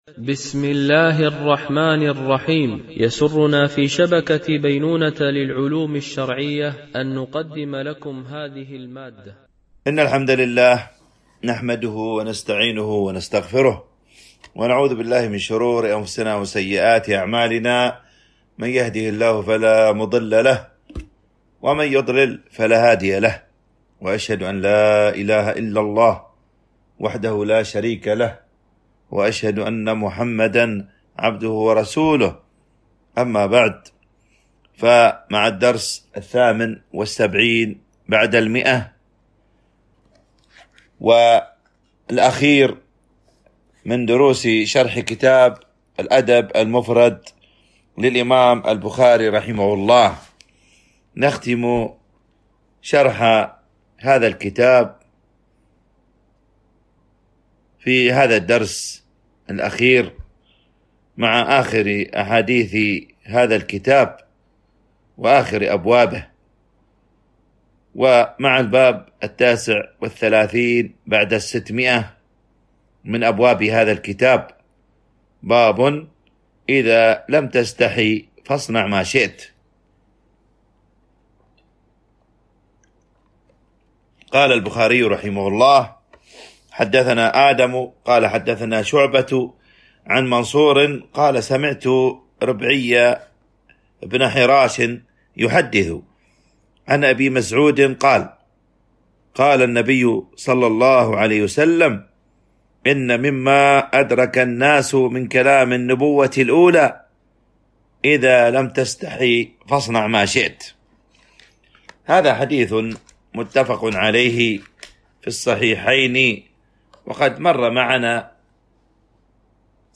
شرح الأدب المفرد للبخاري ـ الدرس 178 والأخير ( الحديث 1316)